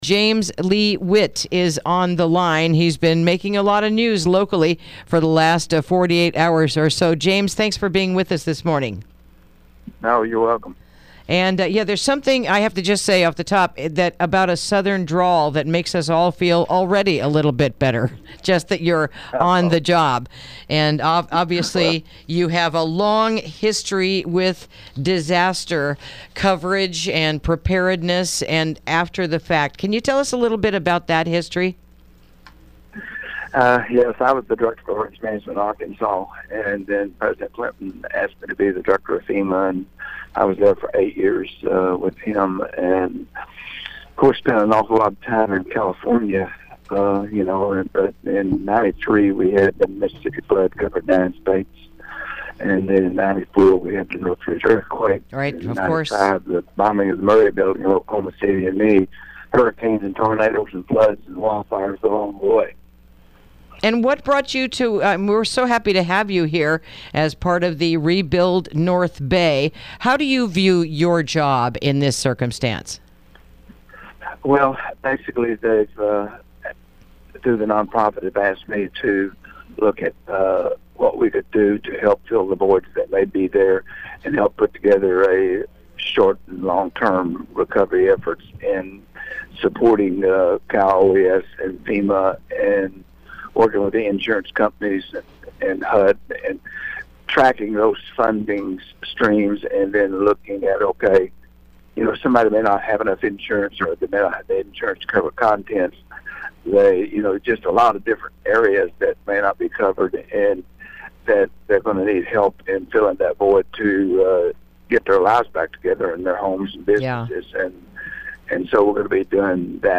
Interview: James Lee Witt